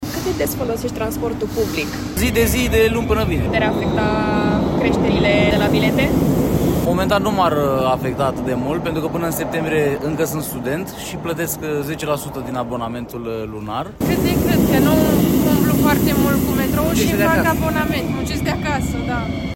14apr-11-Vox-bucurestenii-cu-abonamente-.mp3